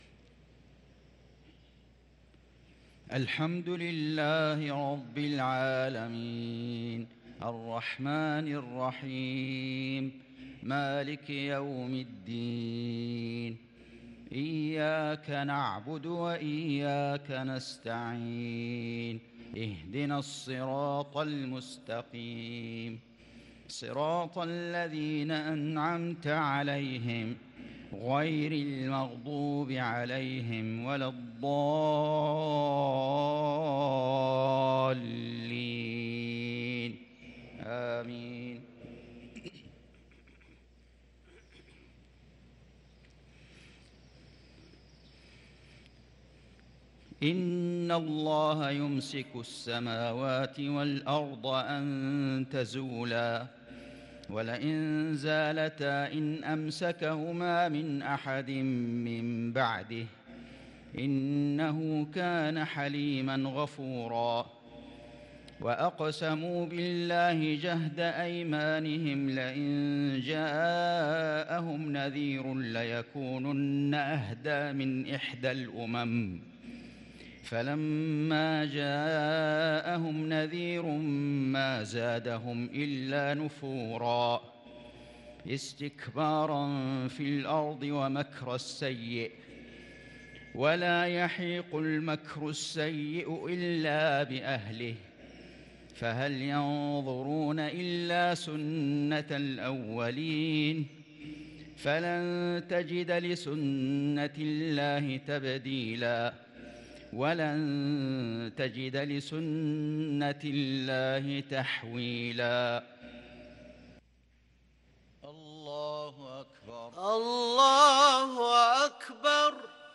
صلاة المغرب للقارئ فيصل غزاوي 28 شعبان 1443 هـ
تِلَاوَات الْحَرَمَيْن .